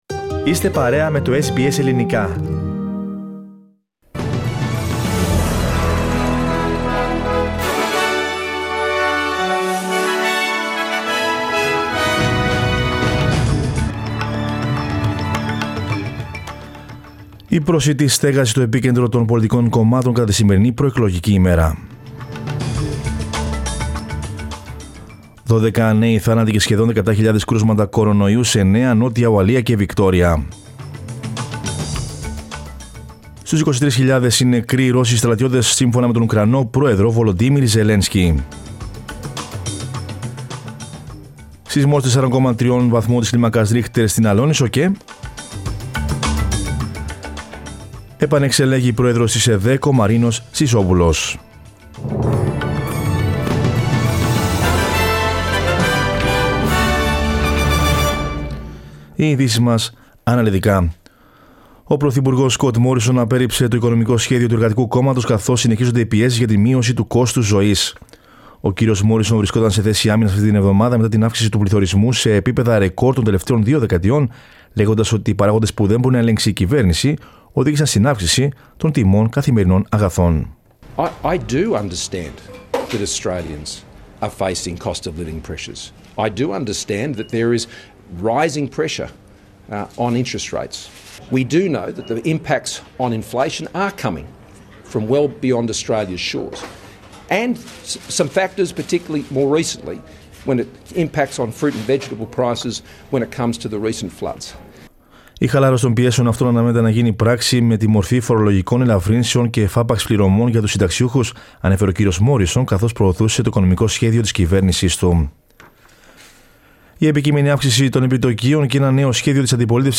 News in Greek from Australia, Greece, Cyprus and the world is the news bulletin of Sunday 1 May 2022.